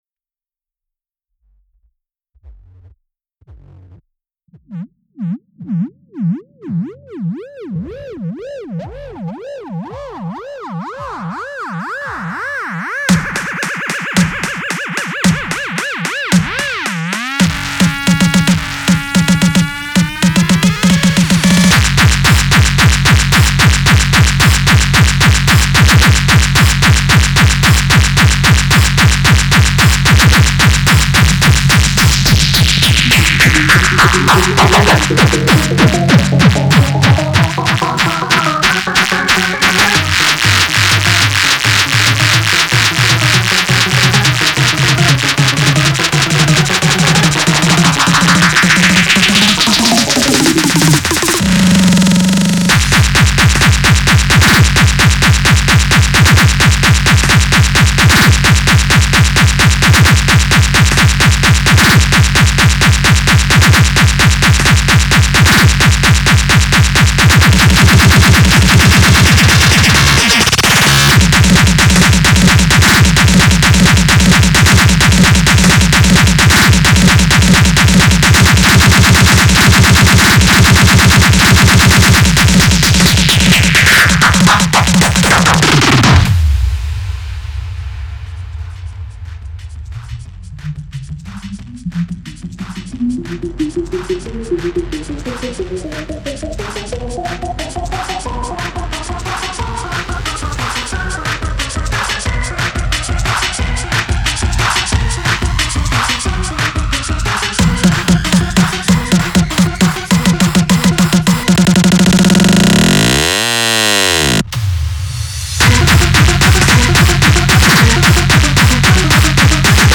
Uptempo Hardcore/Speedcore/Elektronisches Geballer
also summa summarum geht das brett bis an die 260 BPM, deswegen ist es auch so kurz.
das ding ist auch schon gemastert.
irgendwo fehlt mir noch ein sprachsample oder sowas, so fehlts mir noch ein gewisses Alleinstellungsmerkmal
dann habe ich noch ein "plock-geräusch" drüber gelayert.